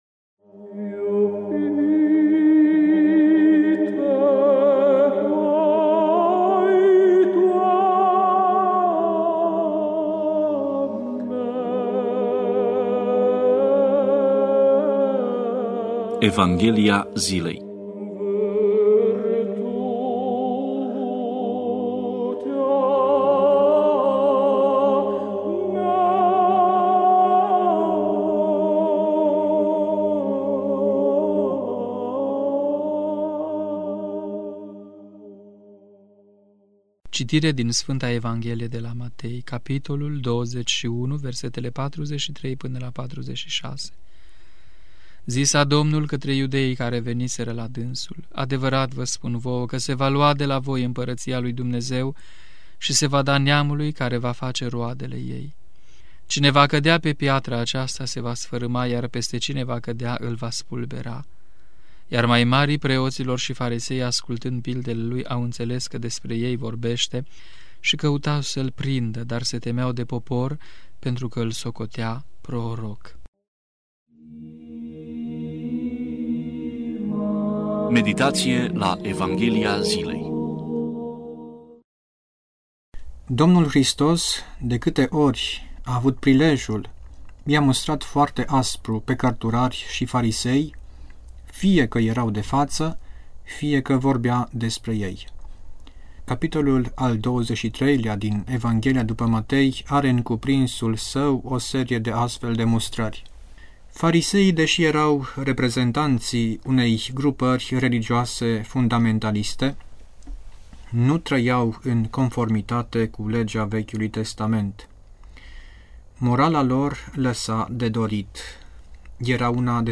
Meditație la Evanghelia zilei